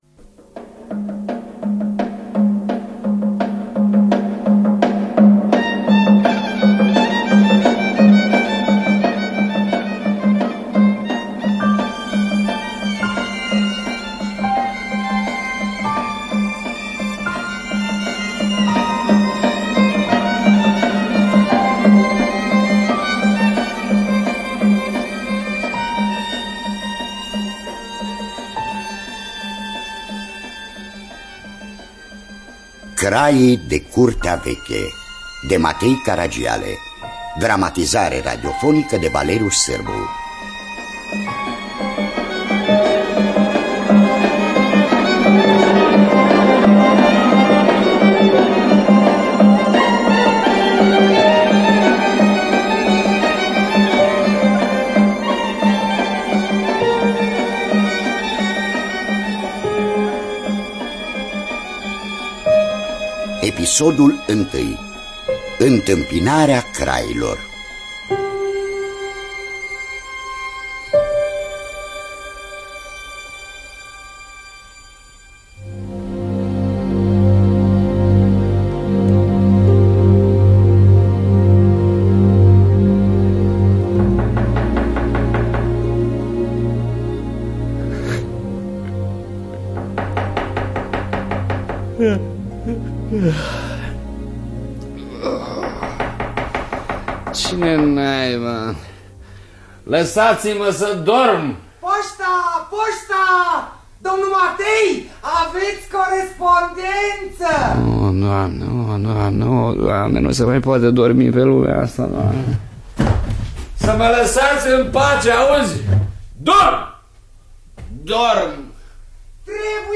Întâmpinarea crailor. Dramatizarea radiofonică de Valeriu Sîrbu.